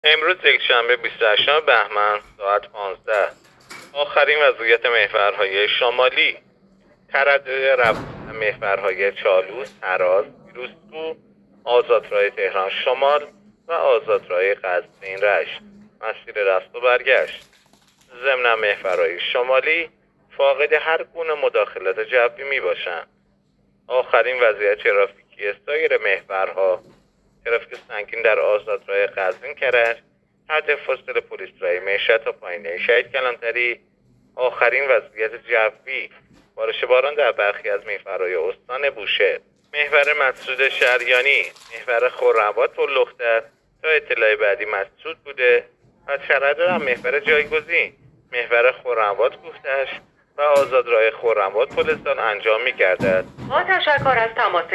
گزارش رادیو اینترنتی از آخرین وضعیت ترافیکی جاده‌ها ساعت ۱۵ بیست و هشتم بهمن؛